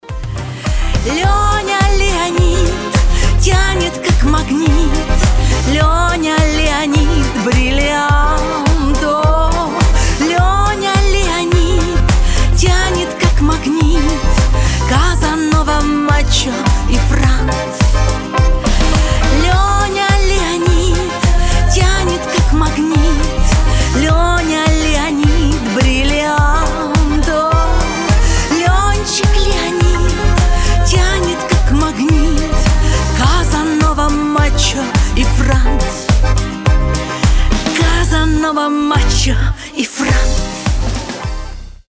позитивные
веселые